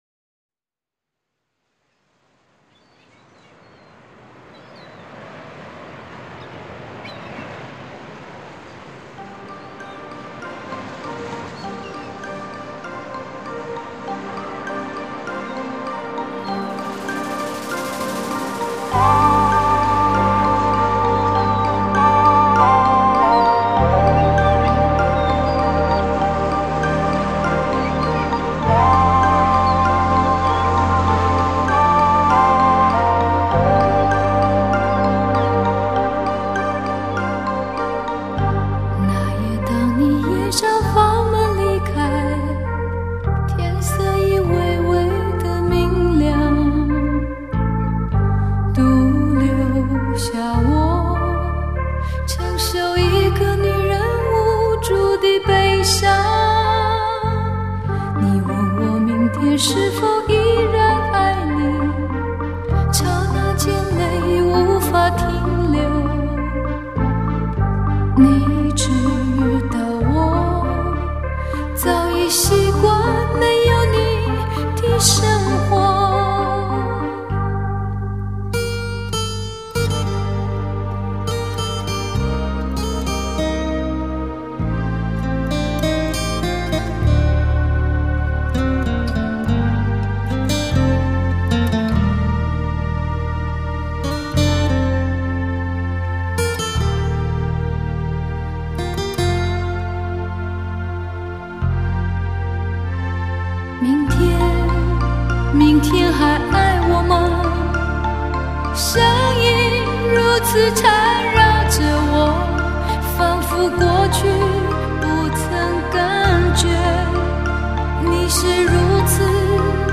洒脱 轻快